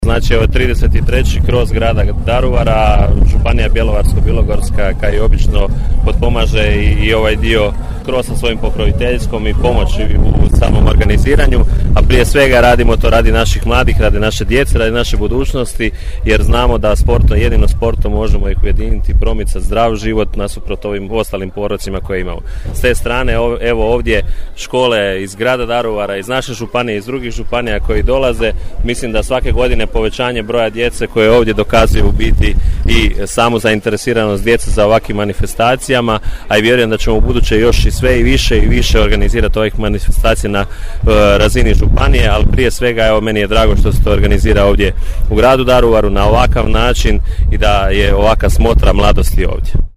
Jučer se na daruvarskom hipodromu održao 33-či Kros grada Daruvara.
Ovu sportsku manifestaciju otvorio je saborski zastupnik Vladimir Bilek: